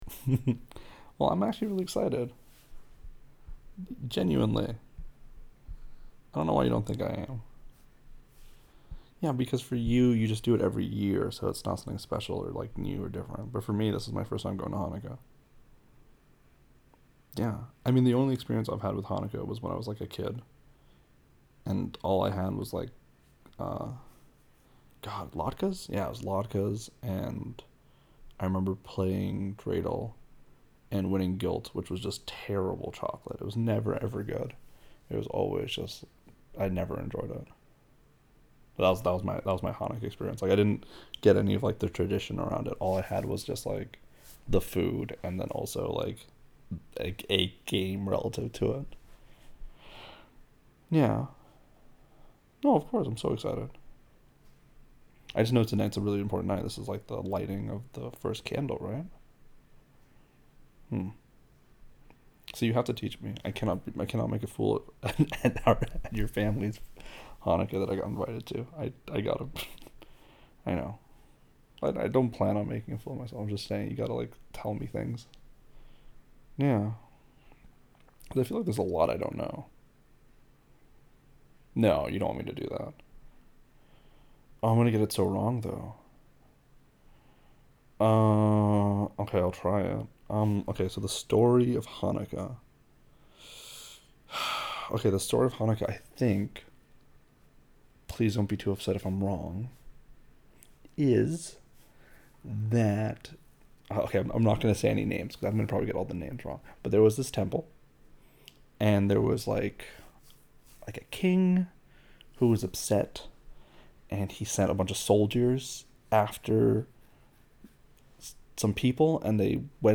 Anyone else hear the clicking/tapping sound all the way through the audio?